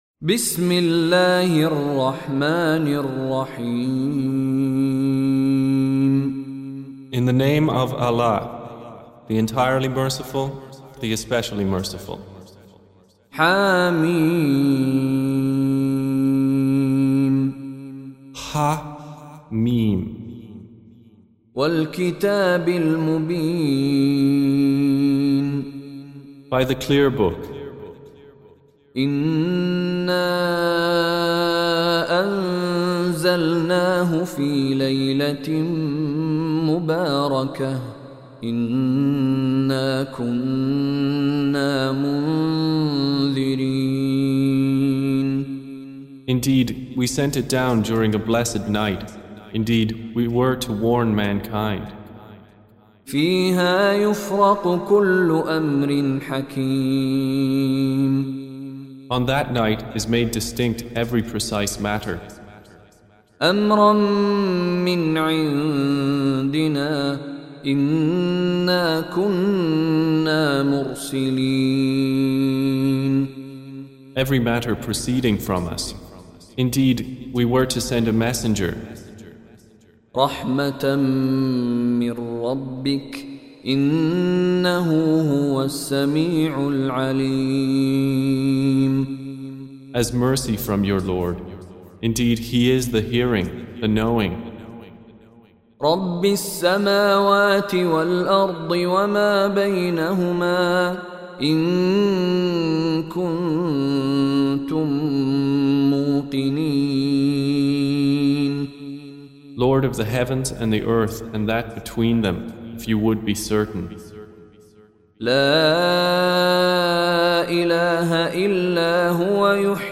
Surah Repeating تكرار السورة Download Surah حمّل السورة Reciting Mutarjamah Translation Audio for 44. Surah Ad-Dukh�n سورة الدّخان N.B *Surah Includes Al-Basmalah Reciters Sequents تتابع التلاوات Reciters Repeats تكرار التلاوات